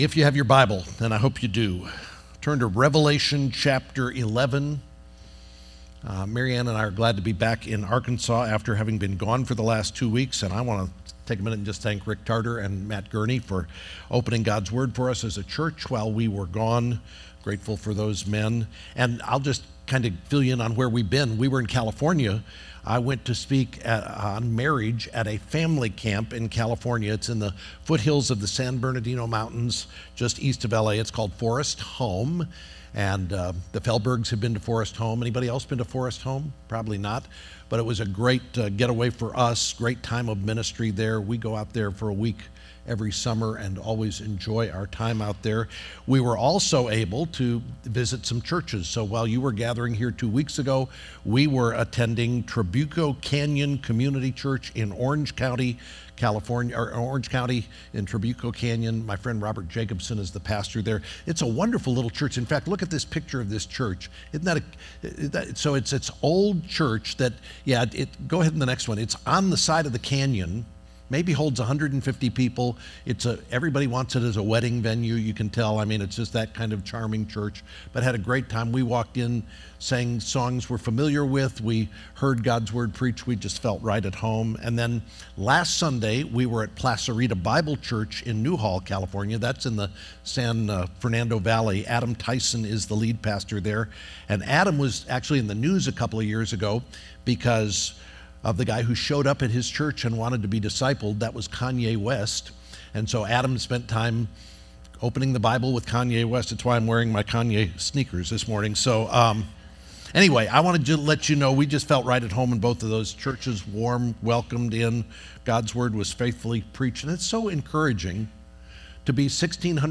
2024 Revelation Revelation 11:3-14 The next sermon in our series through the book of Revelation focusing on the call on our lives to share the good news of the Gospel until Jesus returns from chapter 11.